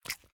latest / assets / minecraft / sounds / mob / frog / eat4.ogg
eat4.ogg